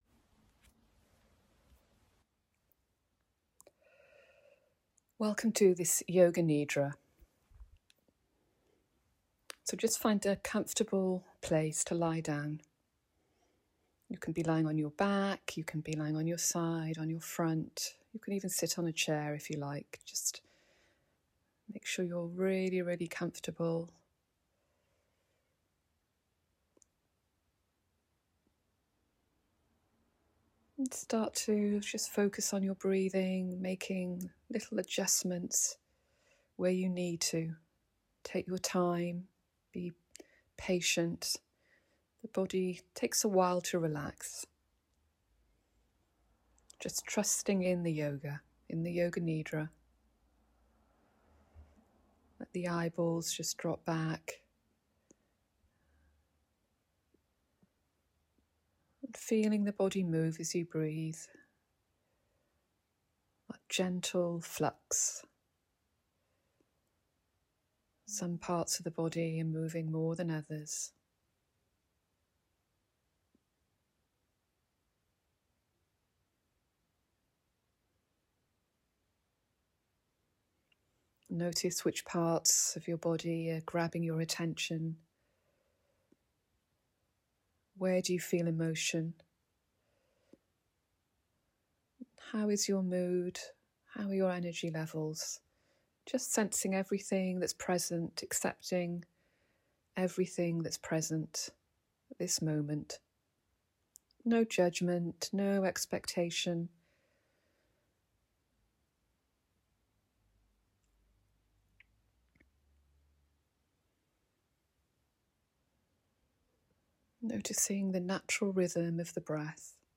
Divine feminine yoga nidra